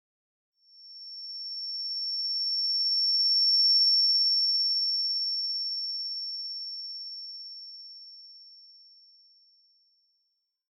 Звуки радара
Радарное кольцо высокого тона анализ местности